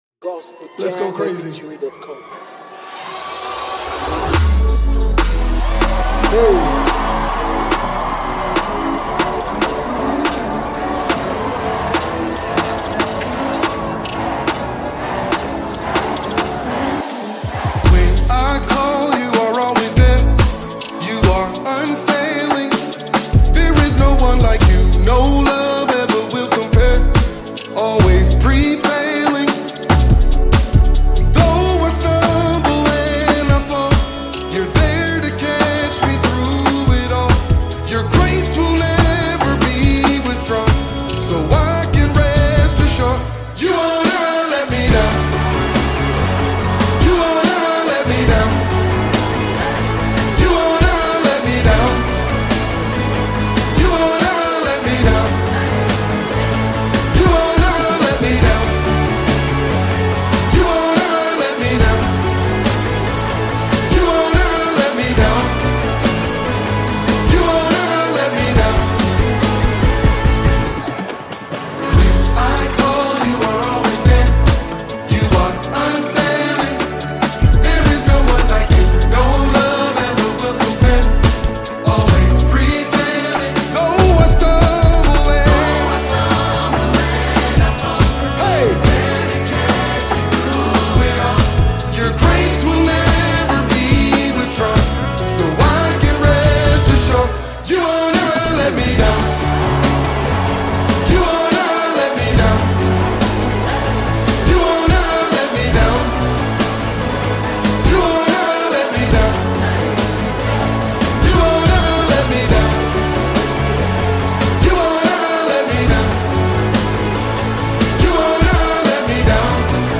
Outstanding Gospel Singer